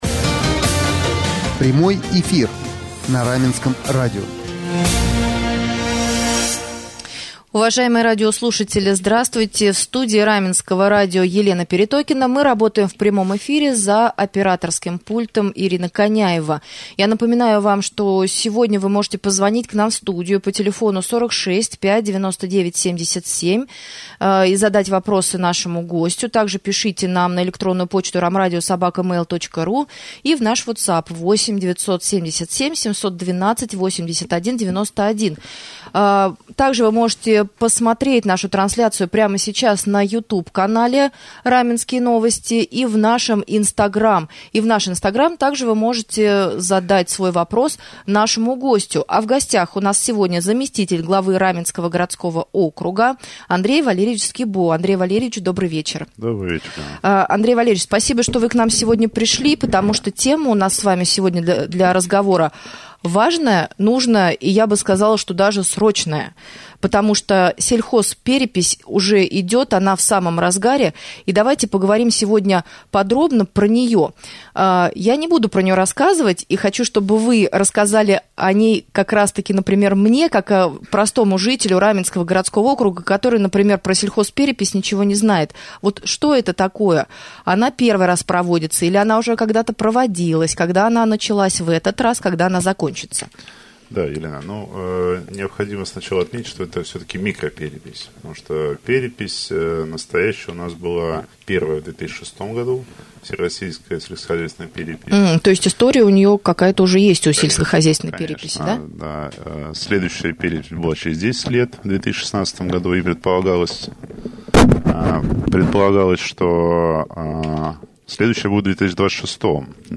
Подробнее о том, кто принимает в ней участие, о работе переписчиков, о том, когда будут известные первые результаты в прямом эфире мы поговорили с заместителем Главы Раменского г.о. Андреем Валерьевичем Скибо.